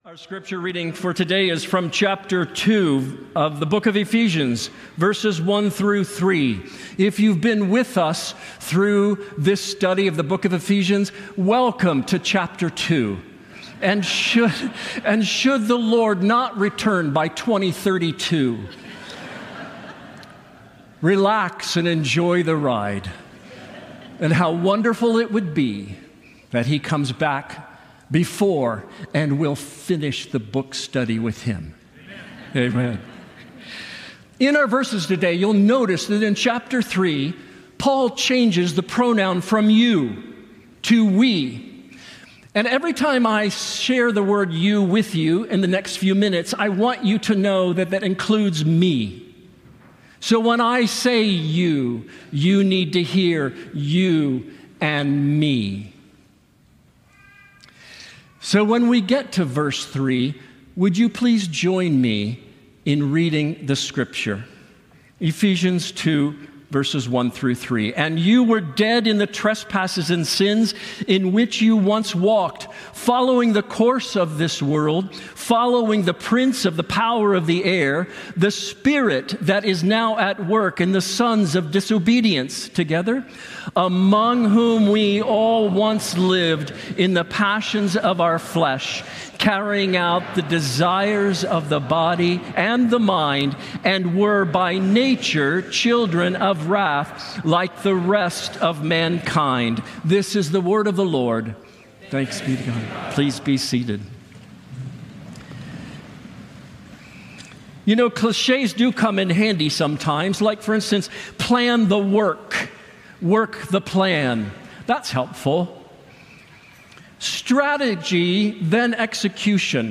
Latest Sermon